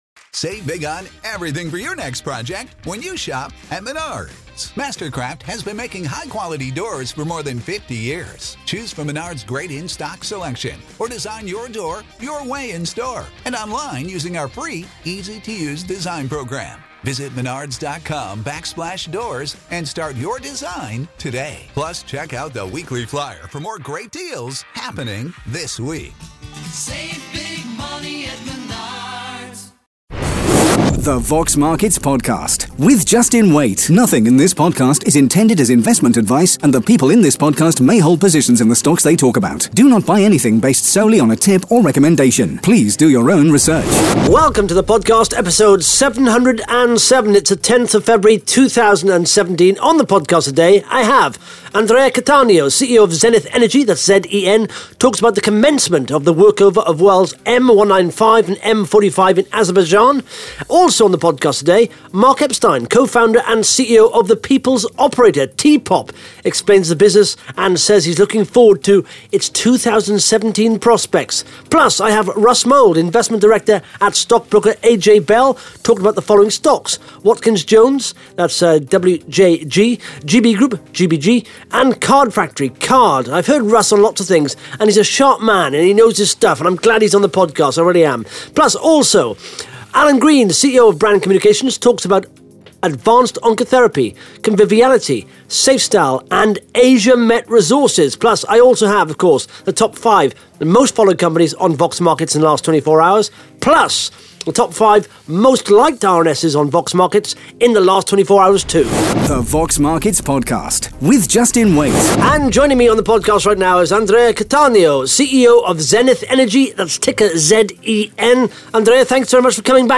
(Interview starts at 1 minute 19 seconds)